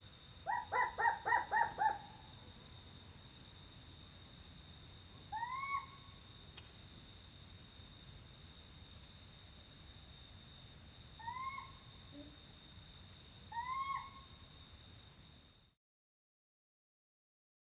4-52-Humes-Owl-Guarding-Call-Soliciting-Calls-Of-Probable-Female.wav